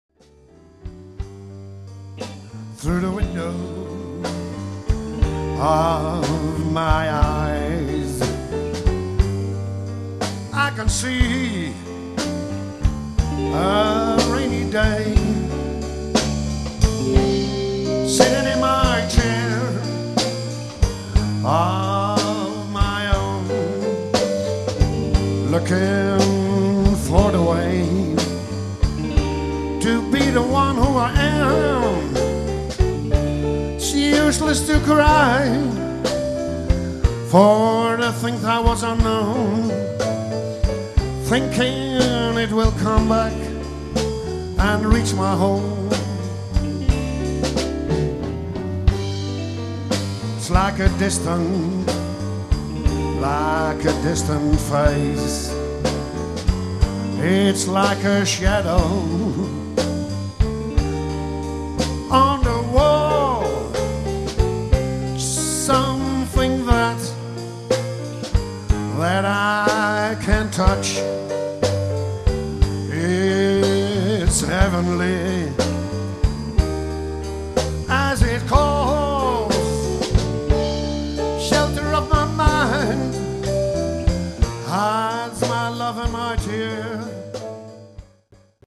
Live opnames